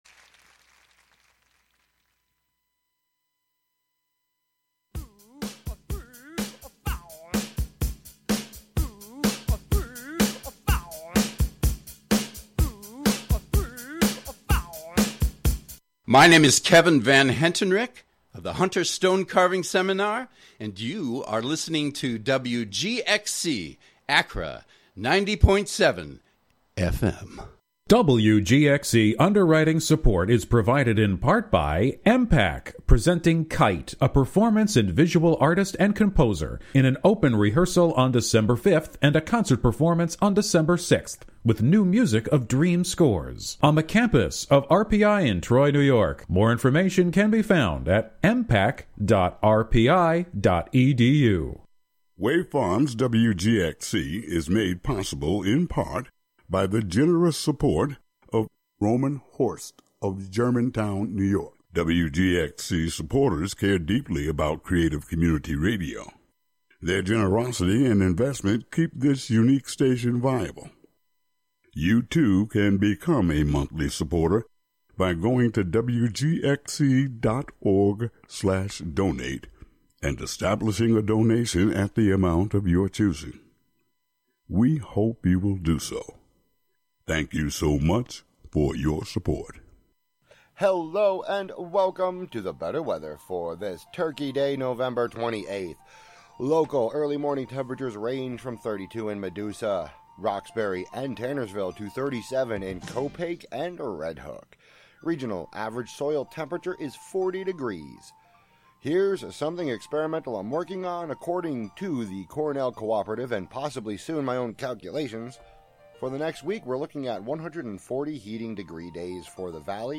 After the news at noon, “Overlooked” is a show focusing on overlooked and under-heard jazz, improvisational, and other fringe music, from early roots to contemporary experiments, a mix of familiar to rare, classic to weird. Live from Overlook Mountain in Ulster County.